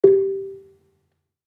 Gambang-G3-f.wav